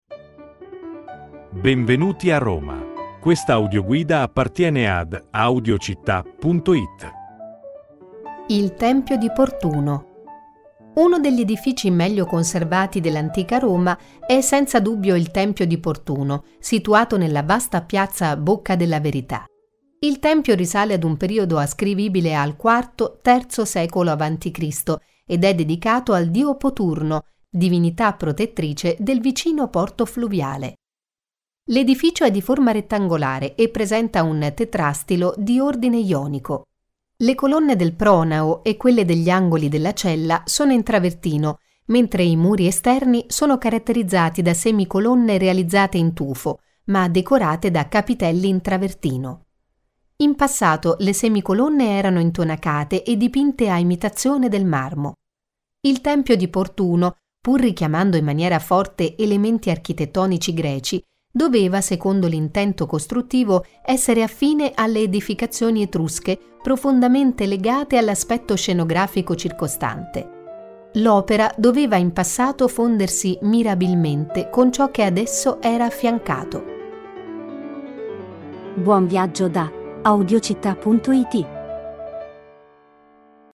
Audioguida Roma - Il tempio di Portuno - Audiocittà